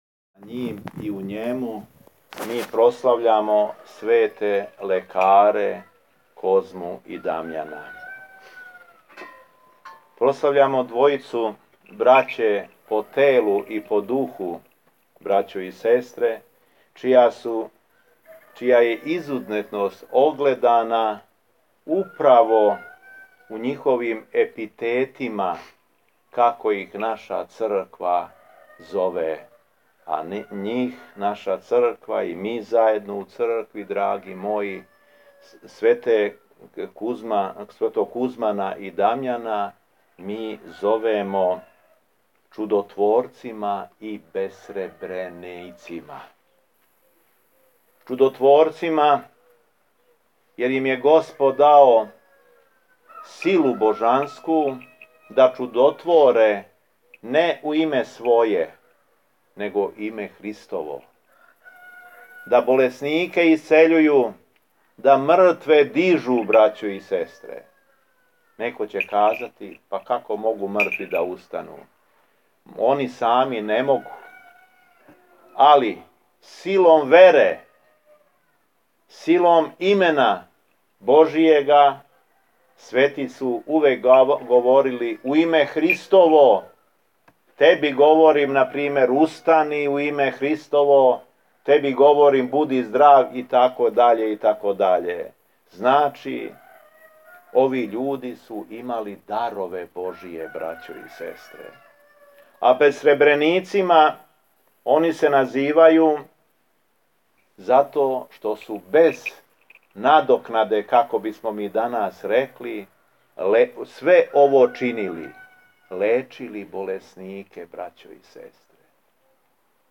У четвртак, 14. јула 2016. године, када наша Света Црква прославља Свете мученике и бесребренике Козму и Дамјана, Његово Преосвештенство Епископ шумадијски Г. Јован служио је Свету Архијерејску Литургију у храму Светих бесребреника Козме и Дамјана у Башину код Смедеревске Паланке.
Беседа Епископа шумадијског Г. Јована
По прочитаном Јеванђељу Преосвећени се обратио присутнима говорећи им о разлогу зашто су људи браћу по телу и браћу по духу, Козму и Дамјана, назвали чудотворцима и бесребреницима.